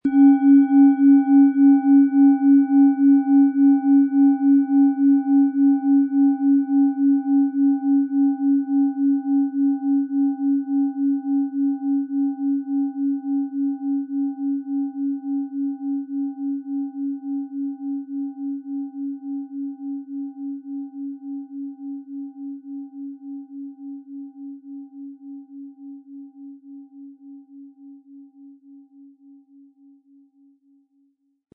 Von Hand getriebene tibetanische Planetenschale Pluto.
Aber uns würde der kraftvolle Klang und diese außerordentliche Klangschwingung der überlieferten Fertigung fehlen.
PlanetentönePluto & Tageston
MaterialBronze